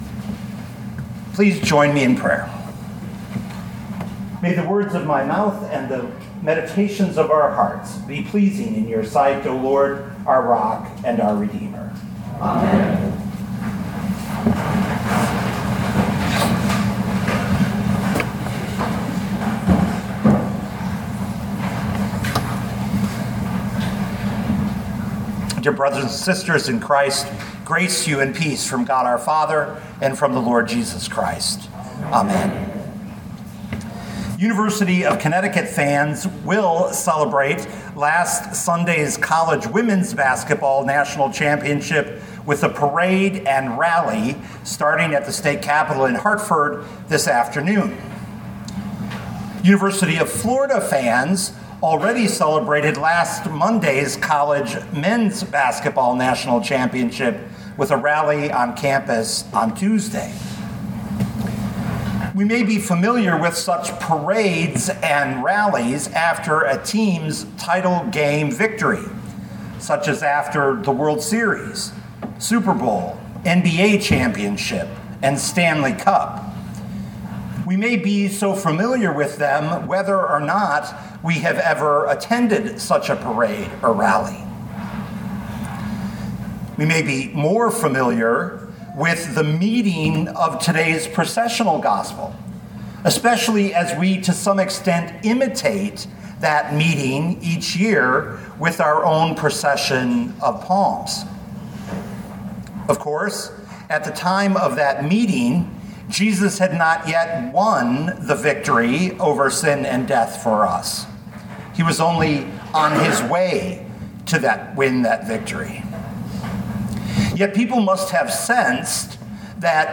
2025 John 12:20-43 Listen to the sermon with the player below, or, download the audio.